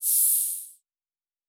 Air Hiss 3_03.wav